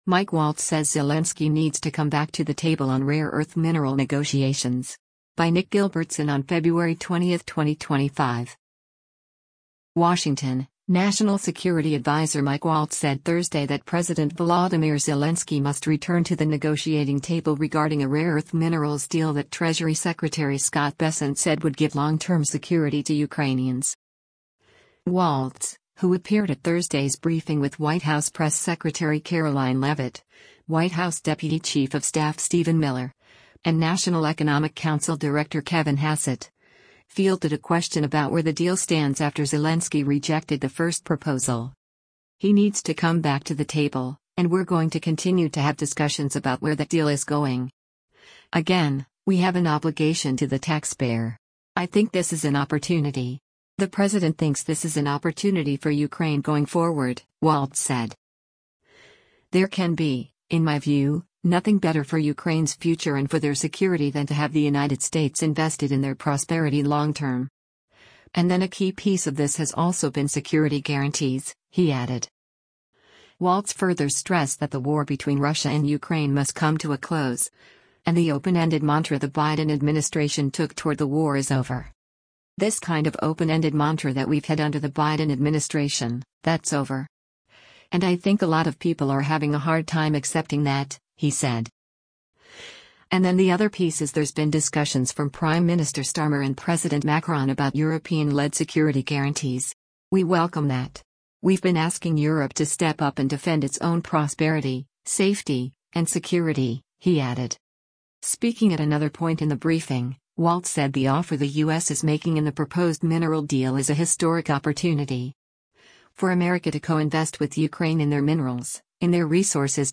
Waltz, who appeared at Thursday’s briefing with White House press secretary Karoline Leavitt, White House Deputy Chief of Staff Stephen Miller, and National Economic Council Director Kevin Hassett, fielded a question about where the deal stands after Zelensky rejected the first proposal.